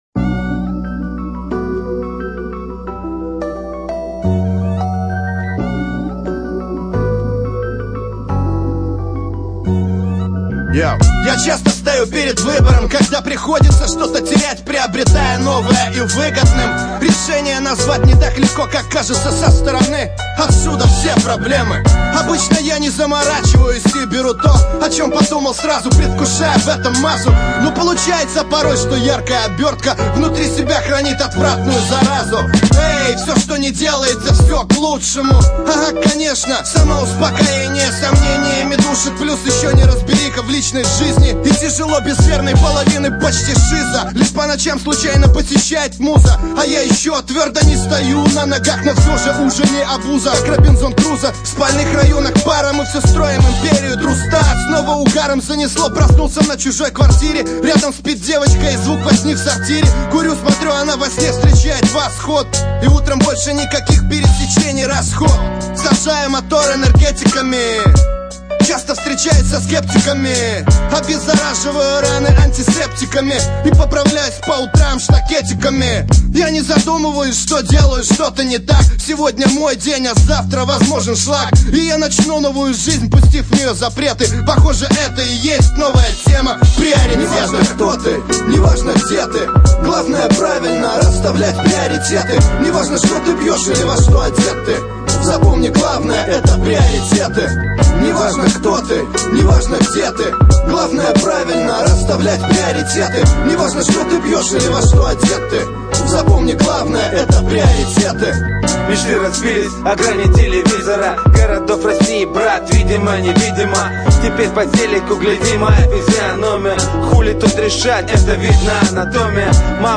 rap музыка